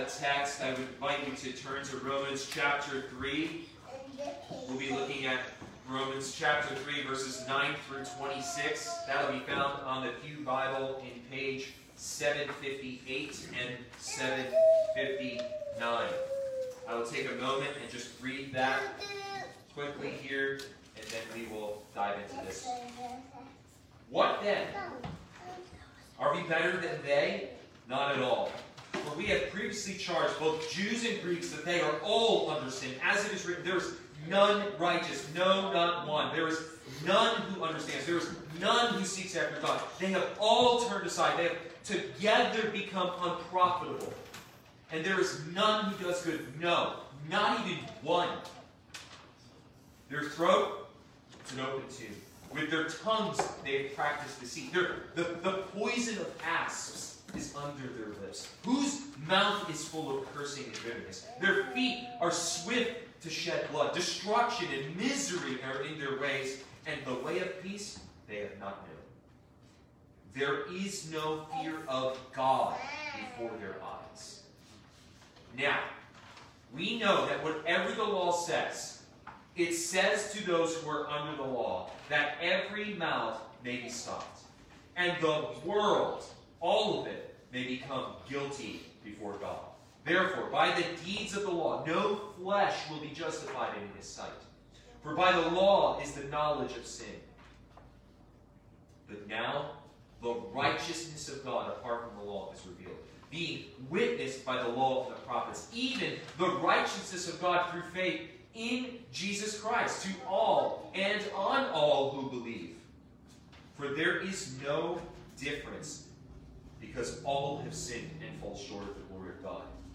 All Sermons
Good-Friday-Service-4_5_21-.m4a